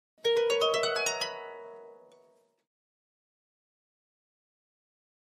Harp, High Strings 7th Chords, Short Ascending Gliss, Type 3